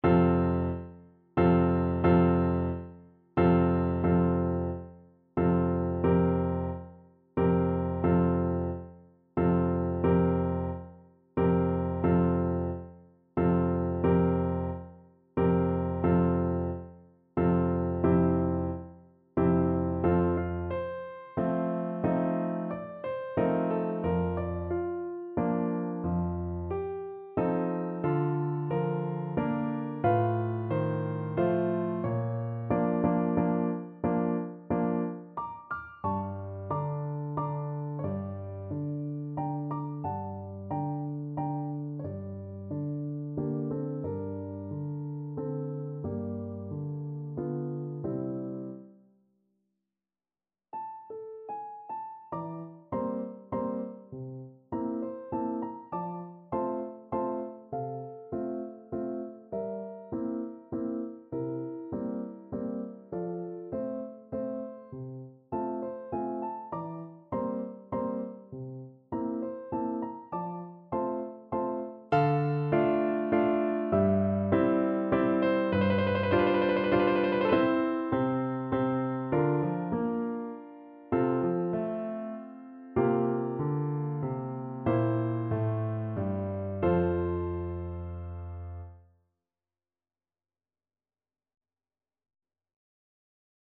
Play (or use space bar on your keyboard) Pause Music Playalong - Piano Accompaniment Playalong Band Accompaniment not yet available reset tempo print settings full screen
F major (Sounding Pitch) (View more F major Music for Tenor Voice )
~ = 90 Allegretto moderato
3/4 (View more 3/4 Music)
Classical (View more Classical Tenor Voice Music)